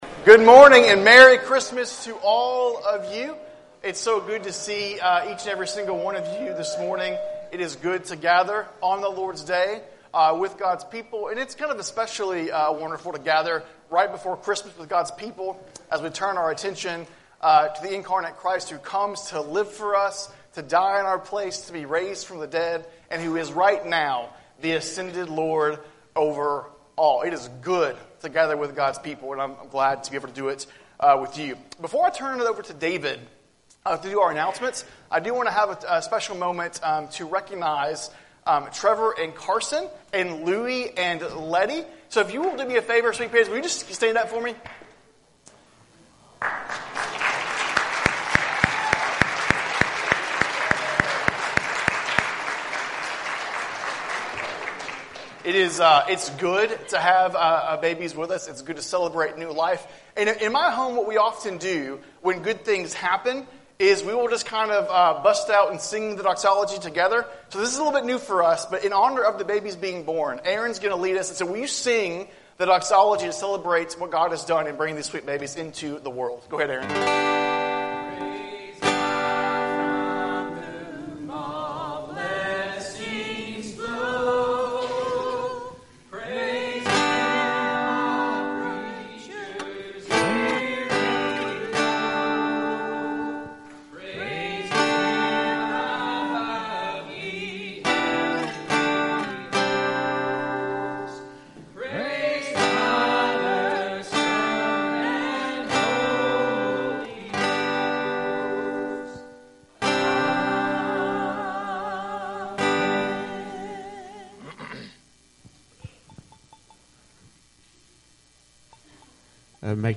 Sermons | Waldo Baptist Church